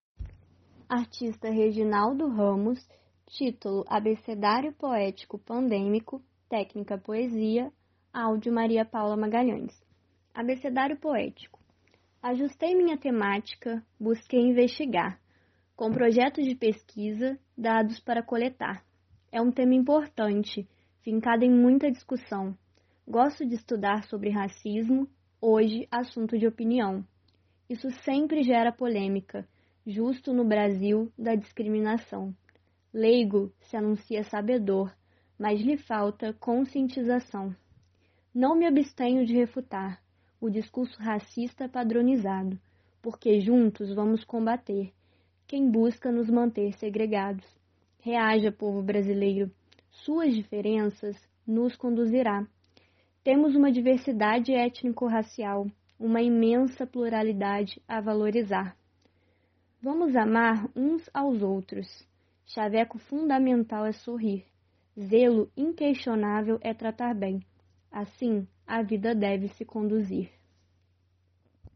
Técnica: poesia
Poesia com voz humana